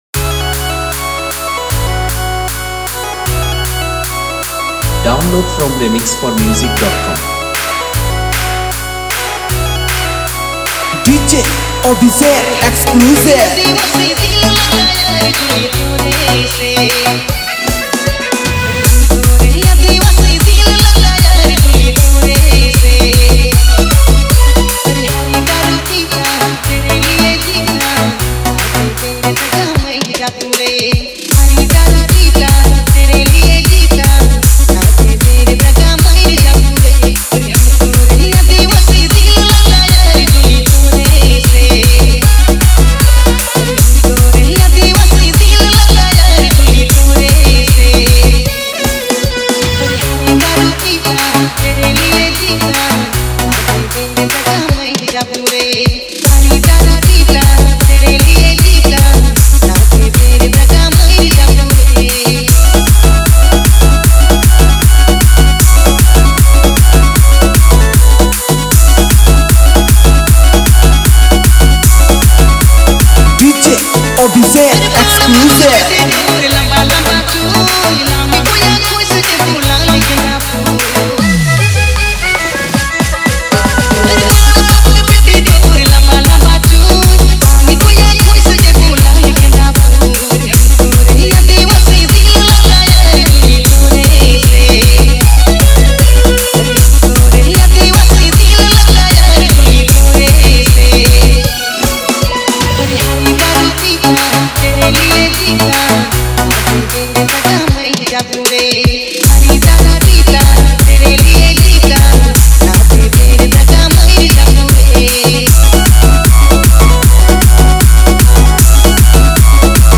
Category : Trending Remix Song